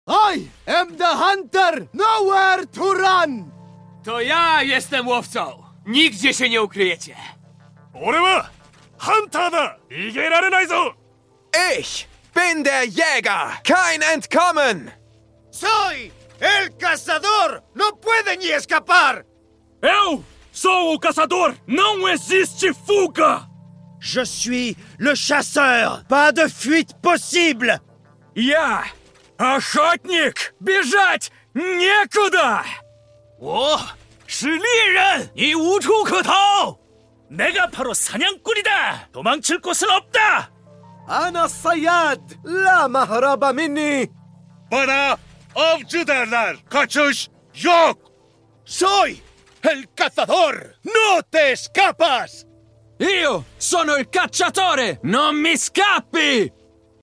All Sova Ultimate Voice Lines sound effects free download
All Sova Ultimate Voice Lines in Every Language | Valorant